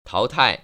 淘汰[táotài]